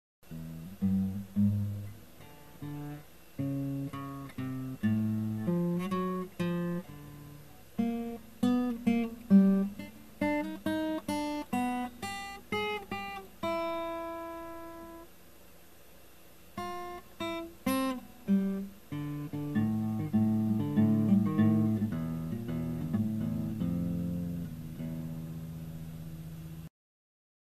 Звуки гитары, струн
Бренчит